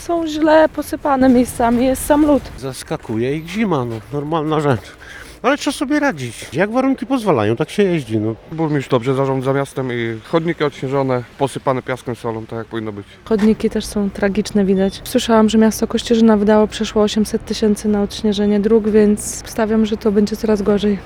A jak mieszkańcy oceniają zimowe utrzymanie dróg i chodników w Kościerzynie?